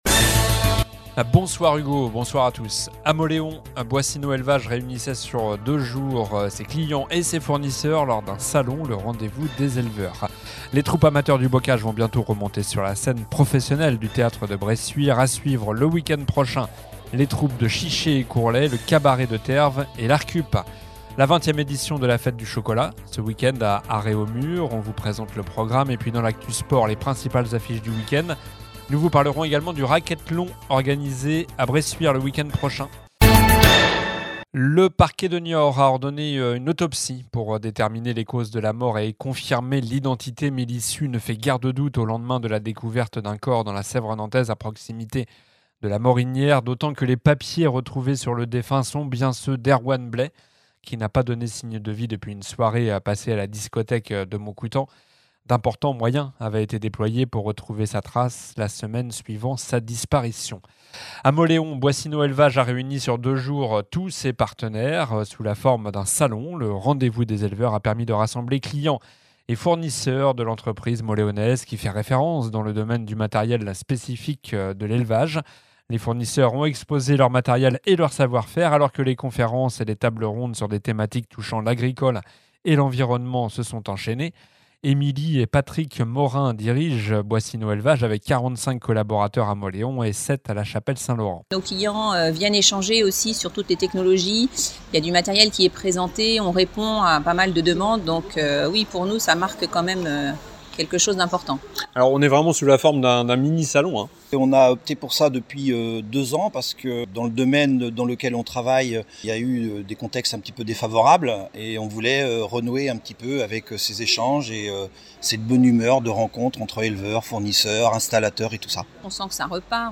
Journal du vendredi 5 avril (soir)